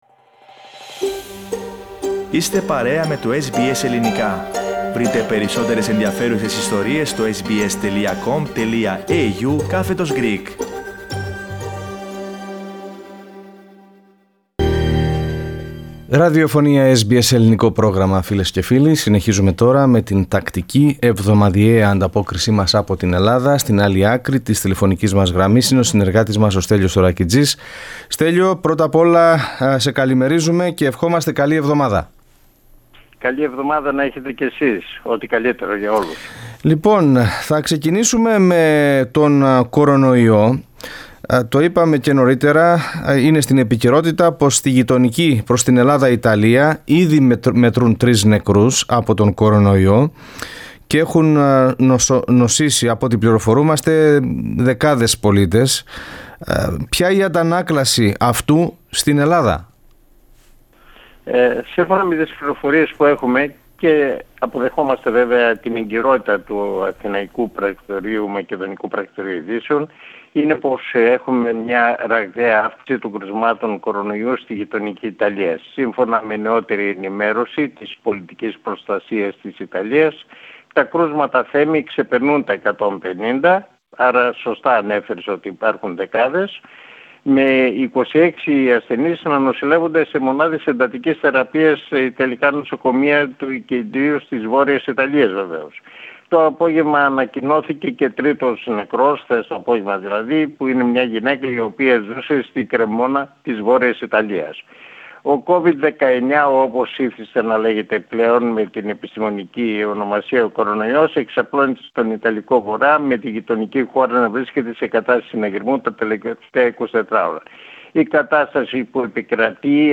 εβδομαδιαία ανταπόκριση